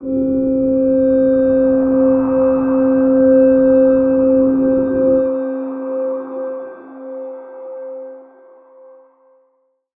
G_Crystal-C5-pp.wav